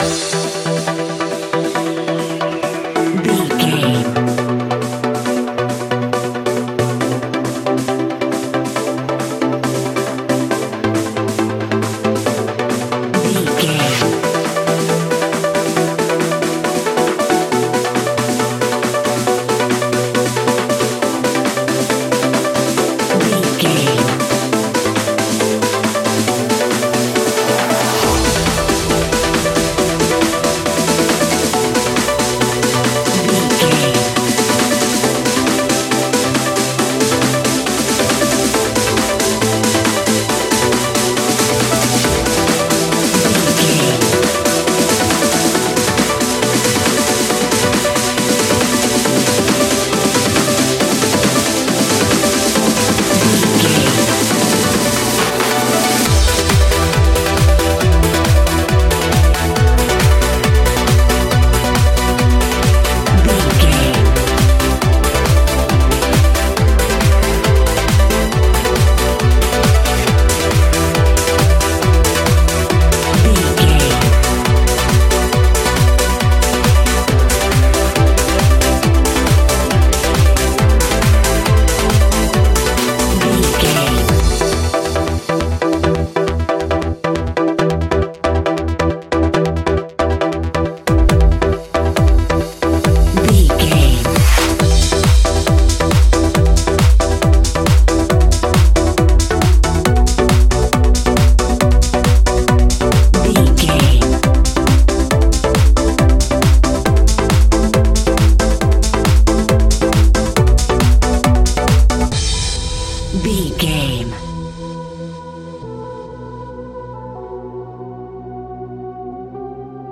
Huge House Trance.
Aeolian/Minor
Fast
frantic
driving
energetic
hypnotic
industrial
powerful
drum machine
synthesiser
acid house
electronic
uptempo
synth leads
synth bass